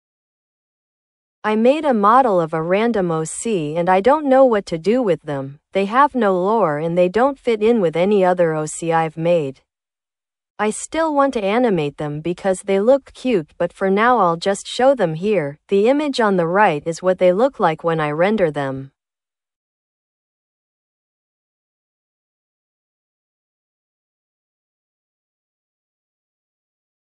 I used google translate for tts on another tab